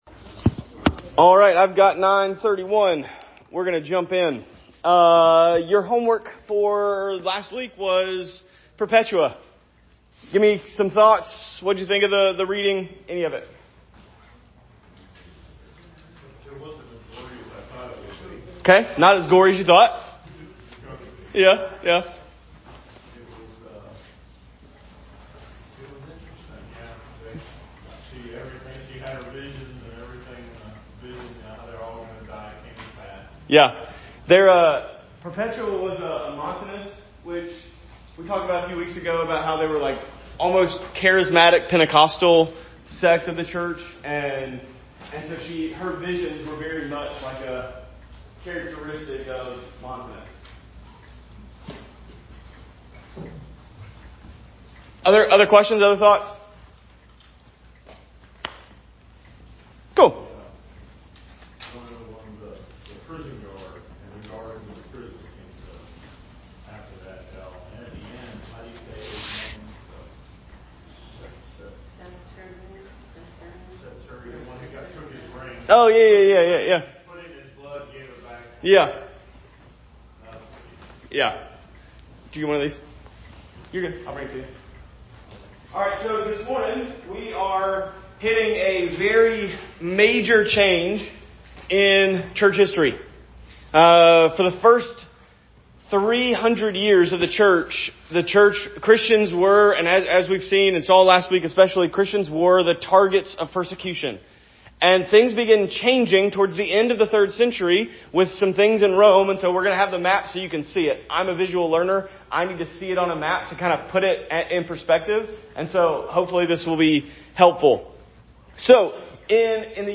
Sept 8 2024 – Church History – Sunday School – From Persecution to Acceptance – Bethel Bear Creek Reformed Church: Media